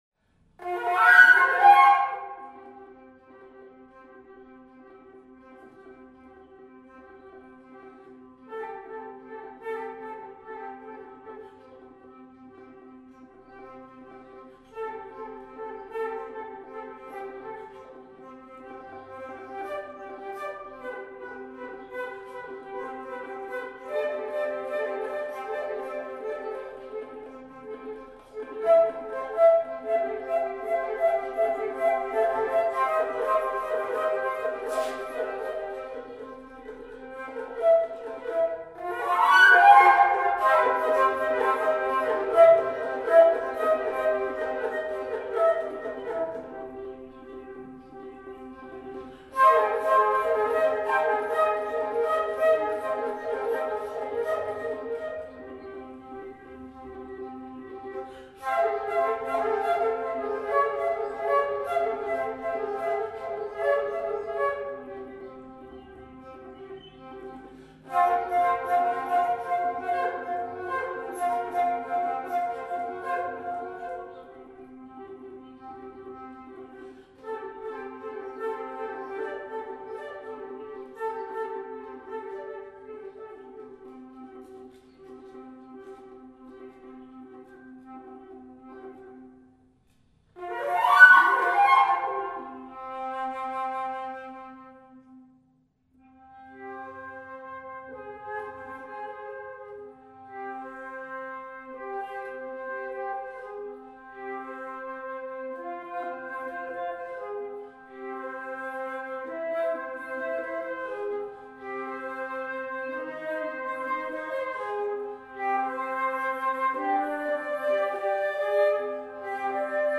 All tan fantasy one for two flutes, to my wife
Live performance
Castellon de la Plana, april the 8, 2011.